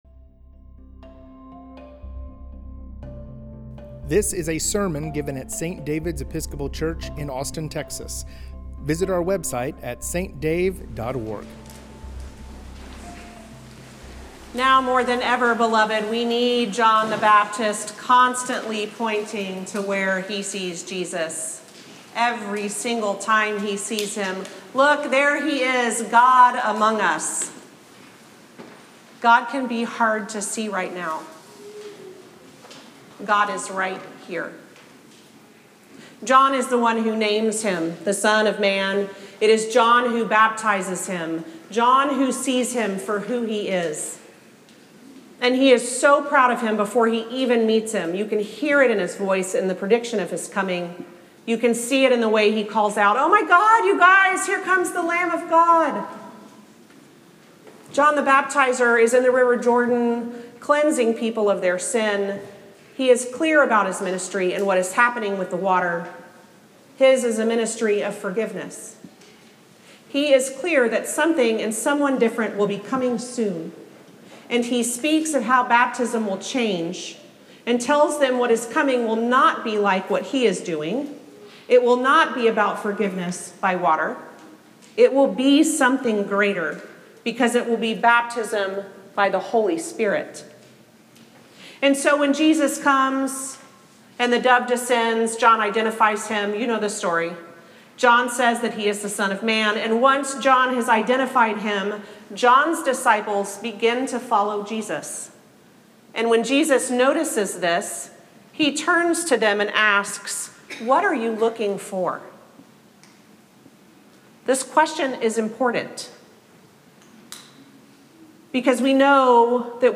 sermon from the second Sunday after Epiphany.
Sermons